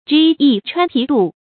杅穿皮蠹 yú chuān shuǐ dù
杅穿皮蠹发音